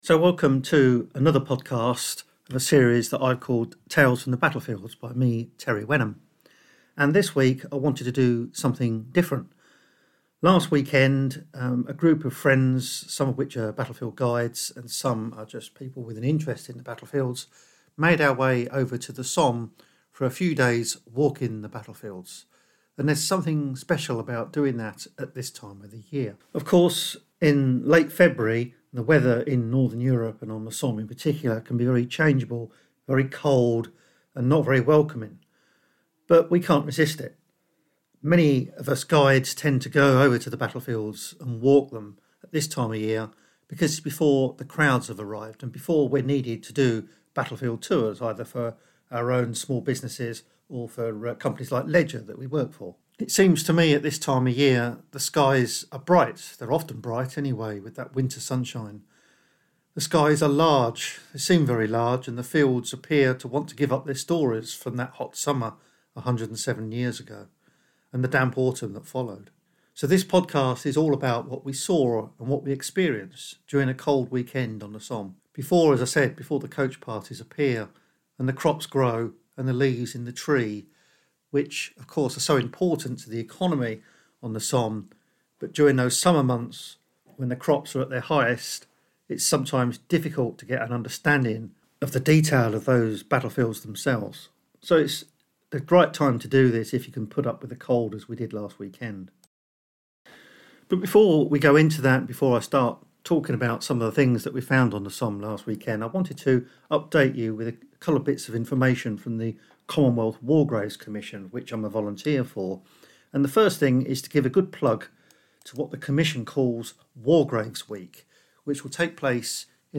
This episode was recorded on a Somme battlefield walk where we tell the stories behind the headstones. We travel to several cemeteries and discover what happened to the men buried there.